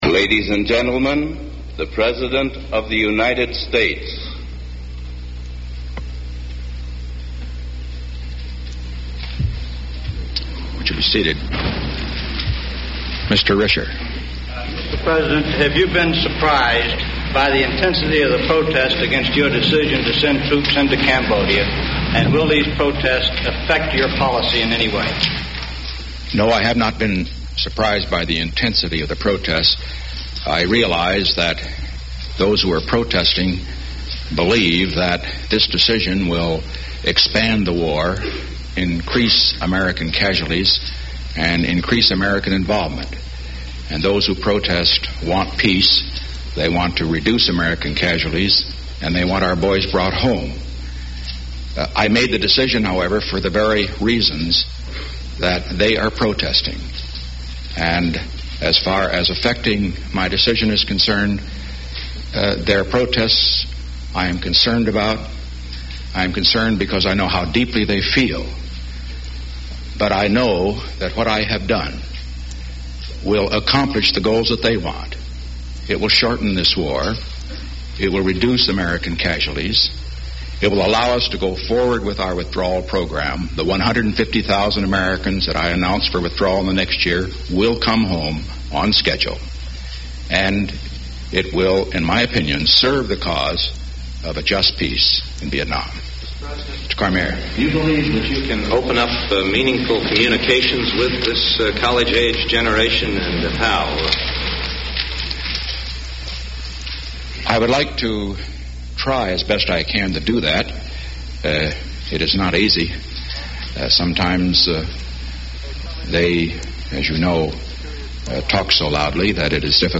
Press conference held at the White House, May 8, 1970